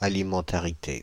Ääntäminen
Ääntäminen France: IPA: /a.li.mɑ̃.ta.ʁi.te/ Haettu sana löytyi näillä lähdekielillä: ranska Käännöksiä ei löytynyt valitulle kohdekielelle.